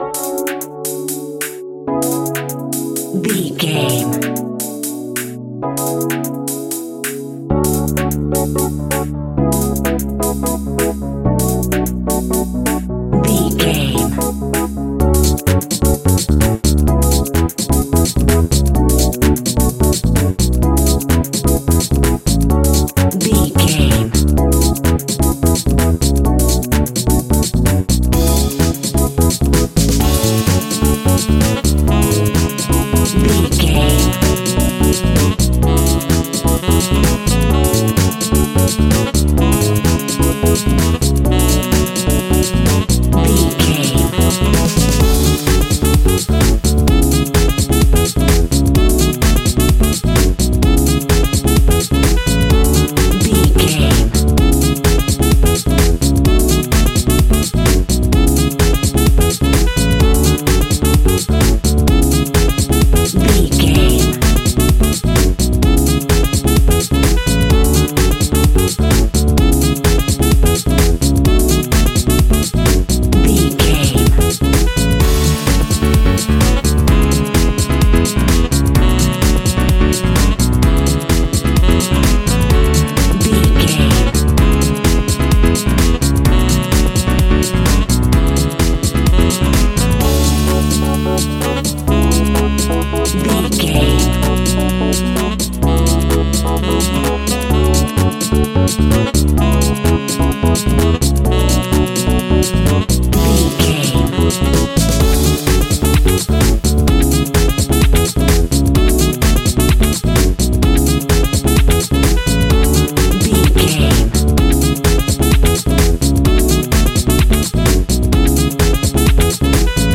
Ionian/Major
groovy
uplifting
bouncy
cheerful/happy
electric guitar
horns
bass guitar
drums
disco
synth
upbeat
instrumentals
clavinet
fender rhodes
synth bass